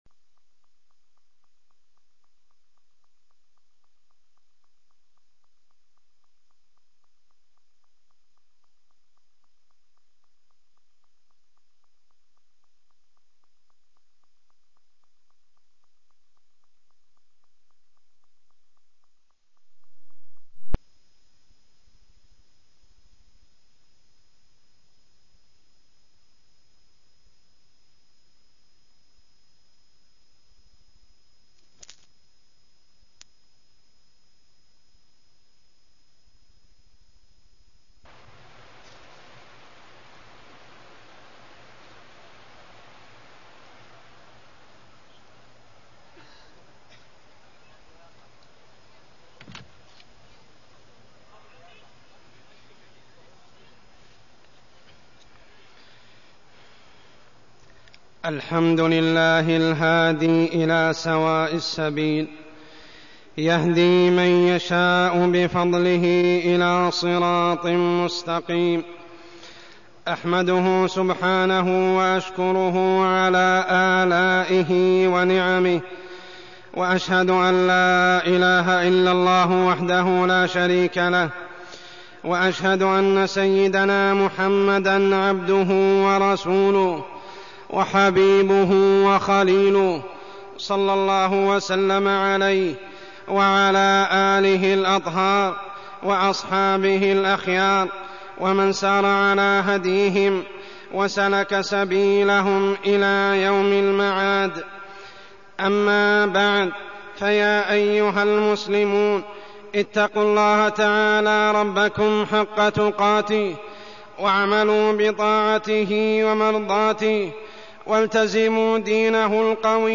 تاريخ النشر ١٨ ربيع الأول ١٤١٧ هـ المكان: المسجد الحرام الشيخ: عمر السبيل عمر السبيل الأم وأثرها في التربية